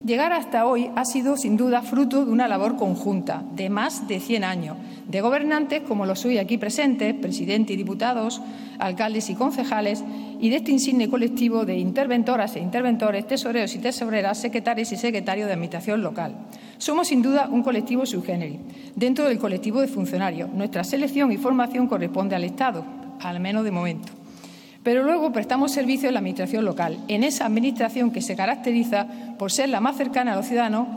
El Consejo General de Secretarios, Interventores y Tesoreros de la Administración Local, Cosital, ha recibo esta tarde, en un acto celebrado en el Patio de Luces del Palacio Provincial de la Calle Navarro Rodrigo, el Escudo de Oro de la Provincia de Almería que la Diputación entrega a aquellas personas, entidades e instituciones que con su labor contribuyen al desarrollo y la prosperidad de la provincia de Almería.